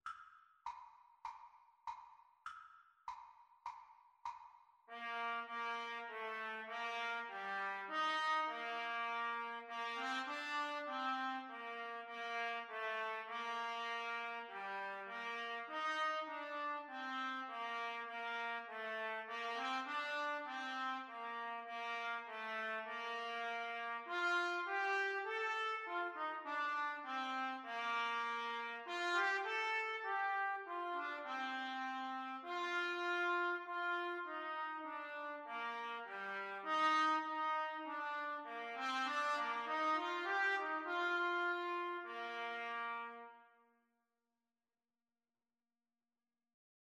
Bb major (Sounding Pitch) C major (Trumpet in Bb) (View more Bb major Music for Trumpet Duet )
4/4 (View more 4/4 Music)
Trumpet Duet  (View more Easy Trumpet Duet Music)